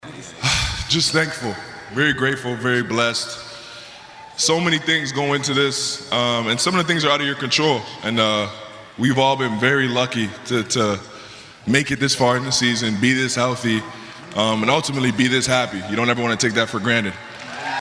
The celebration started with speeches and a rally at the Paycom Center.
Meanwhile Shai Gilgeous-Alexander was thoughtful, as always.